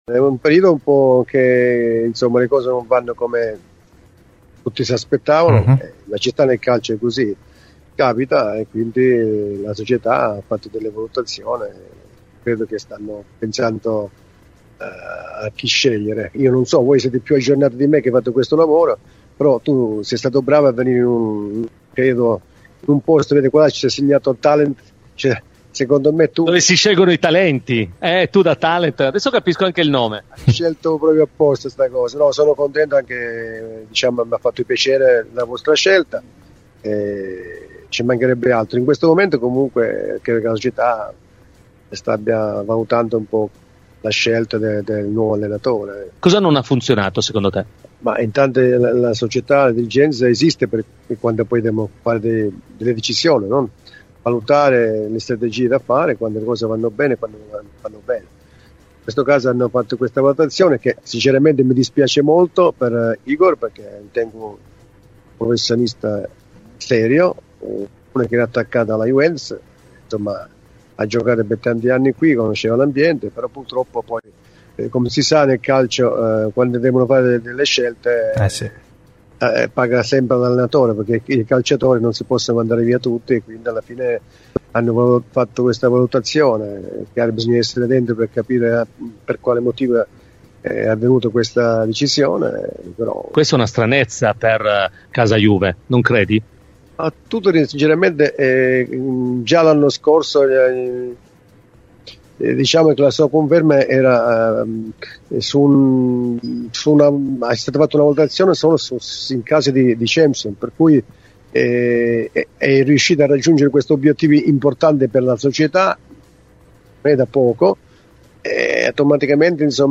Ospite de "L'Ora della Vecchia Signora" su Radio Bianconera